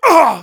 Voice file from Team Fortress 2 German version.
Spy_painsharp04_de.wav